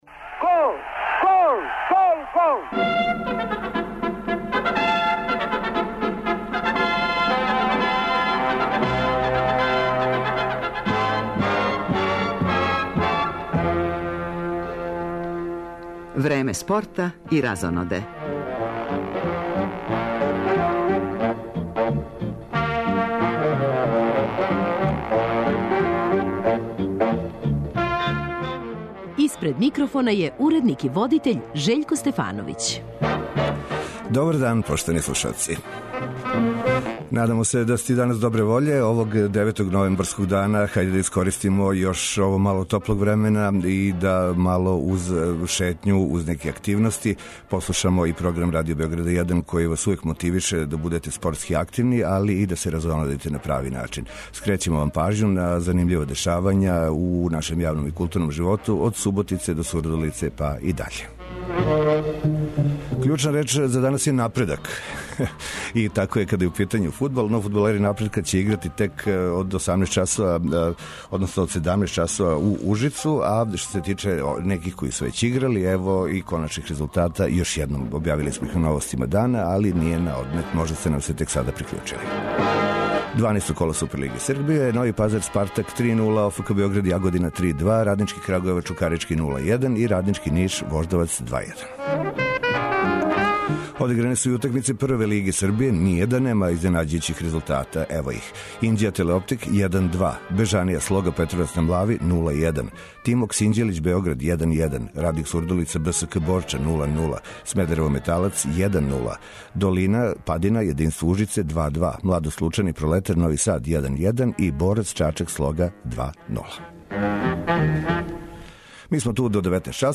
У оквиру емисије, од 17 часова пратићемо пренос реванш утакмице трећег кола квалификација за Лигу шампиона, између Црвене звезде и италијанског Про река. Наши репортери јављаће се и са фудбалске утакмице Партизан - Доњи Срем, неће изостати ни резултати Супер и Прве лиге Србије, као ни значајнијих европских шампиона.
Гост у студију је позната поп певачица Калиопи која сутра, у пратњи чак 14 музичара, има први солистички концерт у Центру `Сава`.